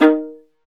Index of /90_sSampleCDs/Roland L-CD702/VOL-1/STR_Viola Solo/STR_Vla2 % marc